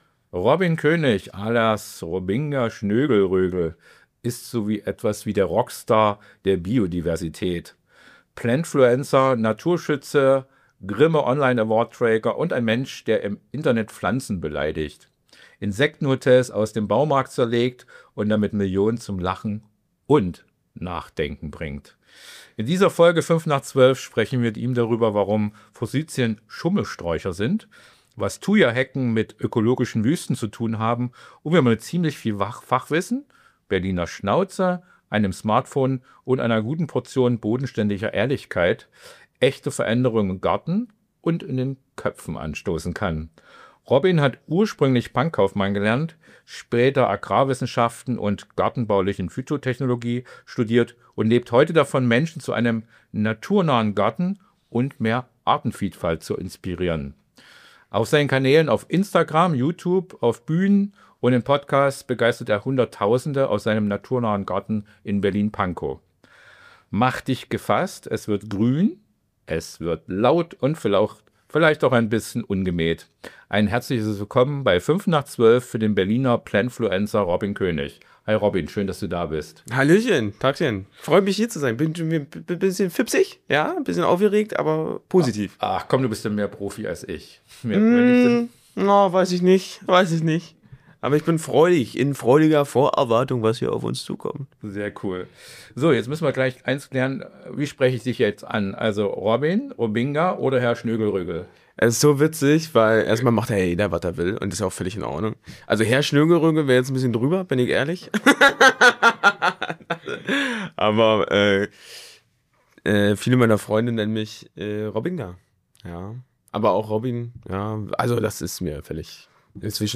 Ein Gespräch über Verantwortung, Reichweite und die Kraft kleiner Veränderungen.